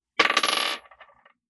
Bouncing Bullet 003.wav